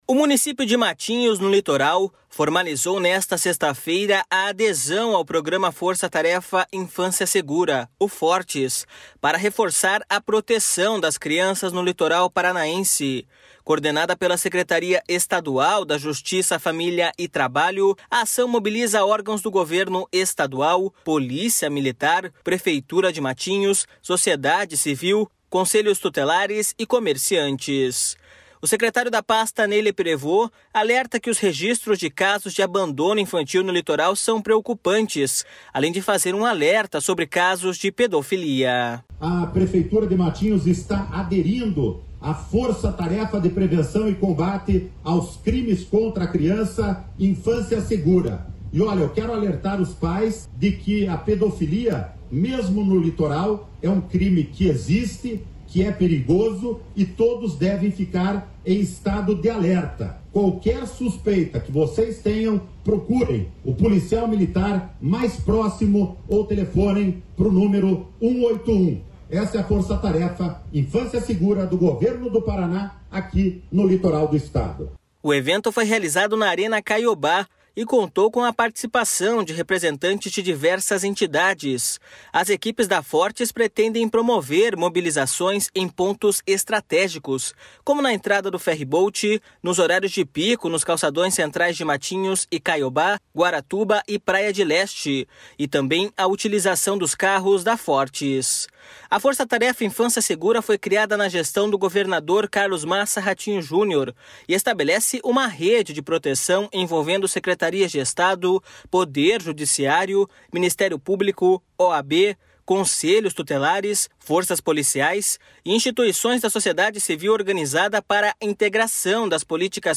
O secretário da pasta, Ney Leprevost, alerta que os registros de casos de abandono infantil no Litoral são preocupantes, além de fazer um alerta sobre casos de pedofilia.// SONORA NEY LEPREVOST.//
O evento foi realizado na Arena Caiobá e contou com a participação de representantes de diversas entidades.